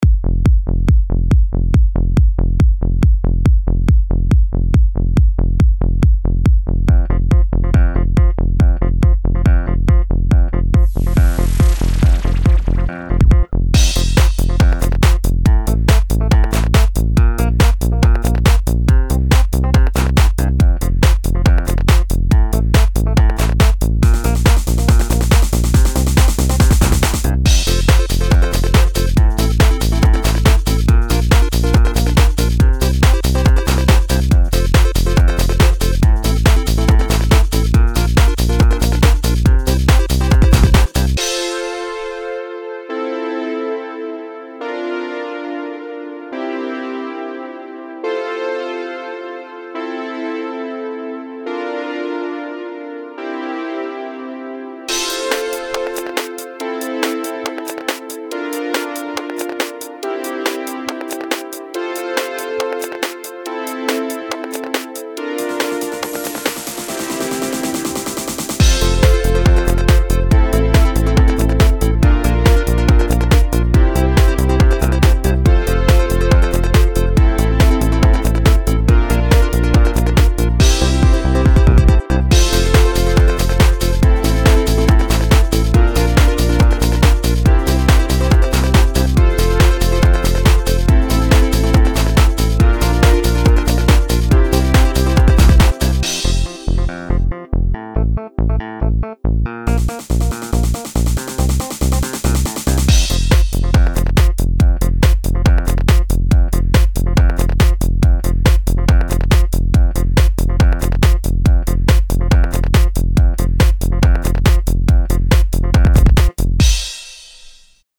Techno Contest 2023
Leute ich bin fast fertig aber ich glaub ich brauch noch wen der mir das Mastern kann, ich bin einfach noch nicht laut genug.